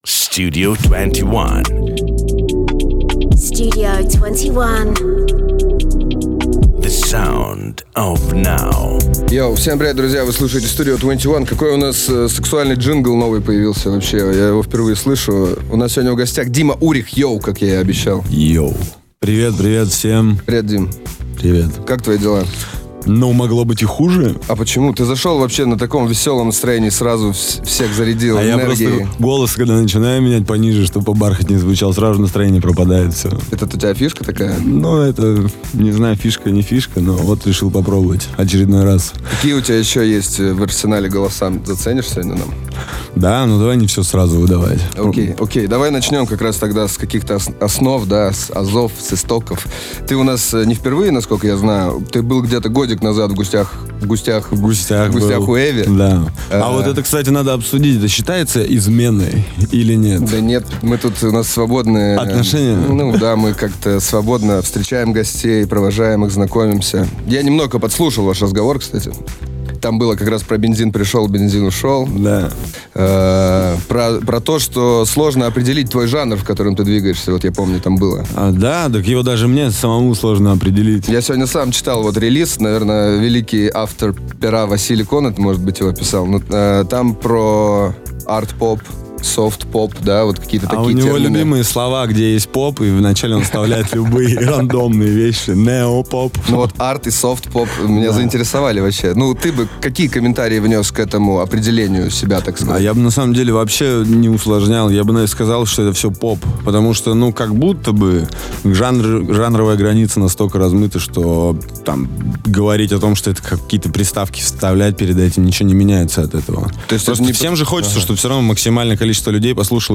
Интервью от 26.02.2025